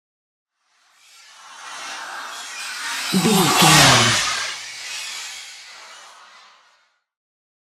Airy pass by horror squeal
Sound Effects
In-crescendo
Atonal
scary
tension
ominous
haunting
eerie